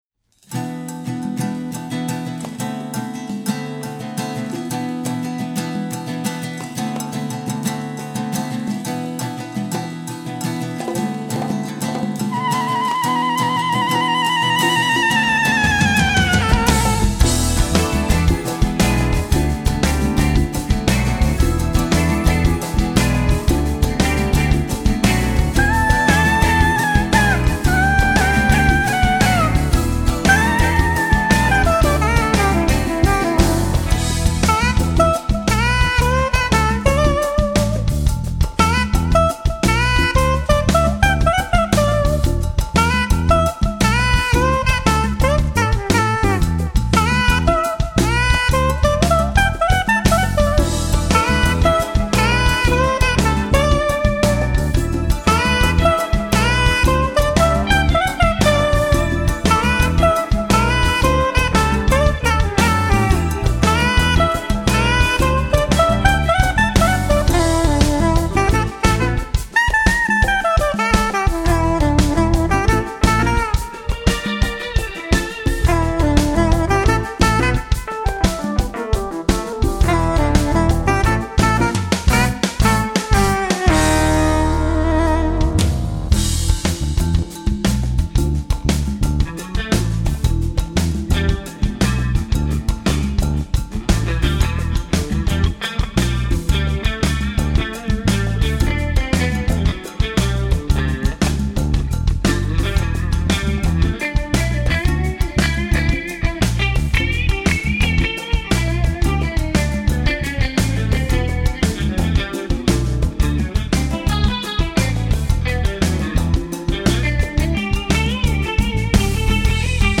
Danish Jazz group
Tenor and Soprano Saxophones/EWI
Bass
Keyboards
Drums
Guitar
Trumpet
Percussion